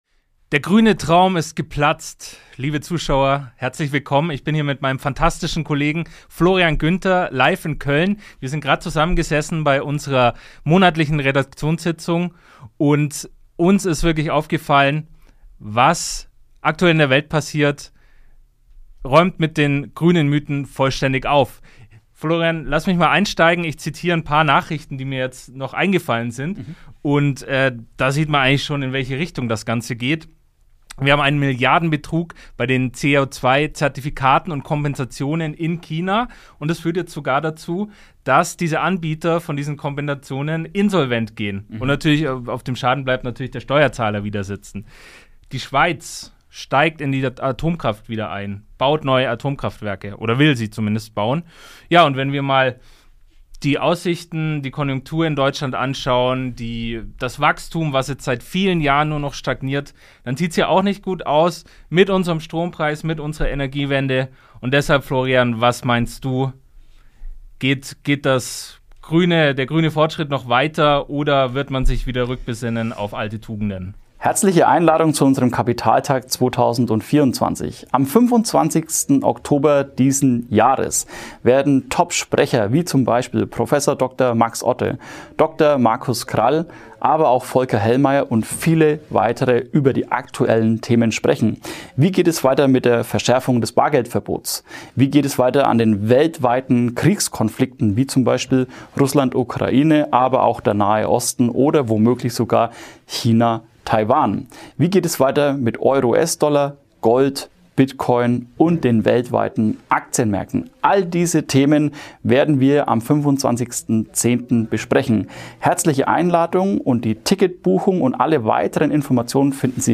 in unserem Studio Köln.